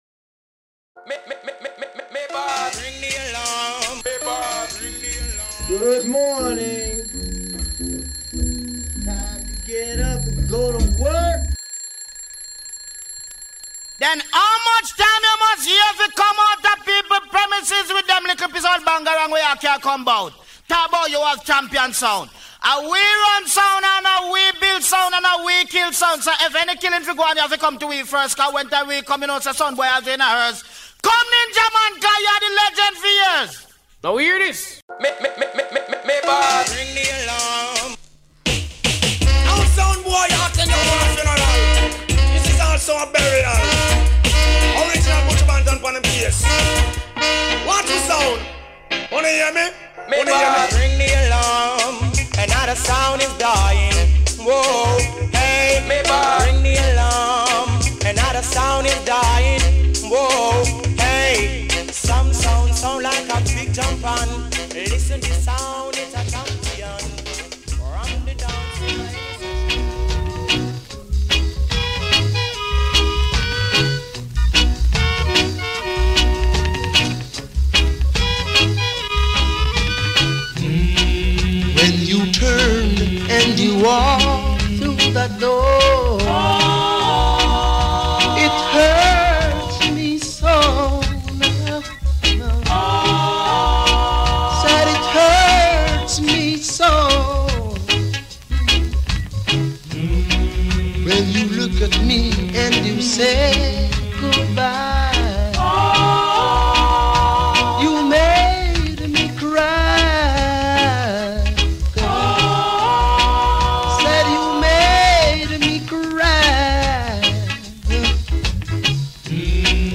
Emission - Ring The Alarm RING THE ALARM – Session n°5 Publié le 2 octobre 2024 Partager sur… Télécharger en MP3 dub , reggae Laisser un commentaire Laisser un commentaire Annuler la réponse Votre adresse e-mail ne sera pas publiée.